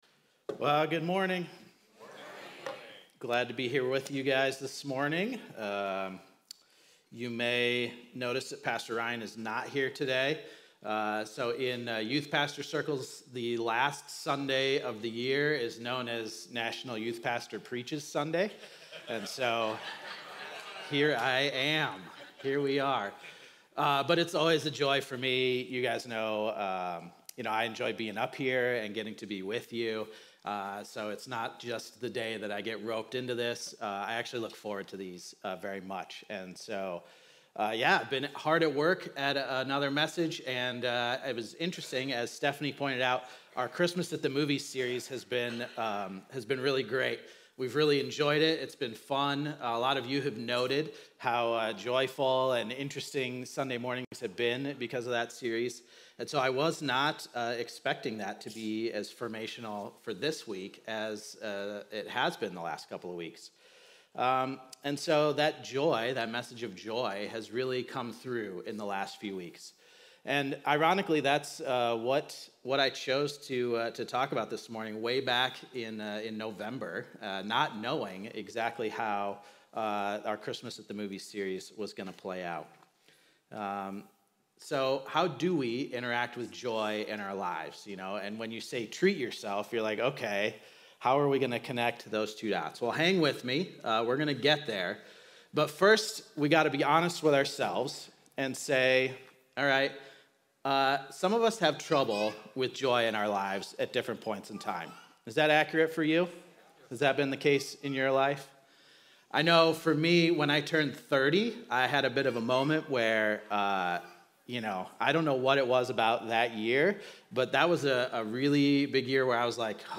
keyboard_arrow_left Sermons / Stand Alone Series Download MP3